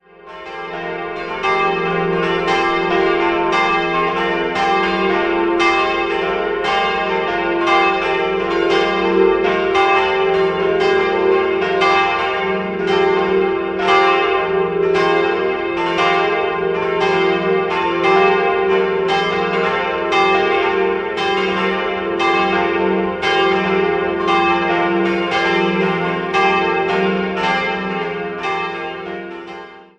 Die Ausstattung des Aresinger Gotteshauses erfolgte 1895 im Stil der Neugotik. 4-stimmiges ausgefülltes F-Dur-Geläut: f'-g'-a'-c'' Alle Glocken wurden 1950 von der Firma Otto in Bremen-Hemelingen gegossen.